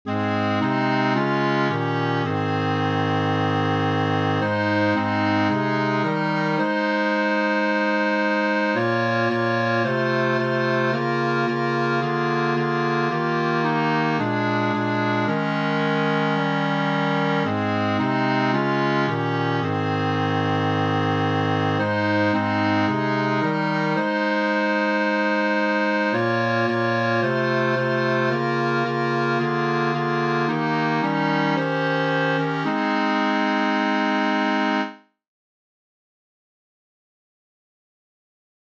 Finale playout, four parts with clarinet, etc.